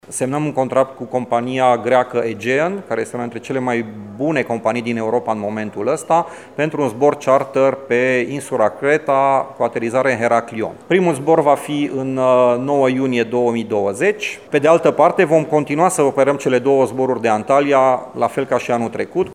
Reprezentantul unei agenții de turism din Transilvania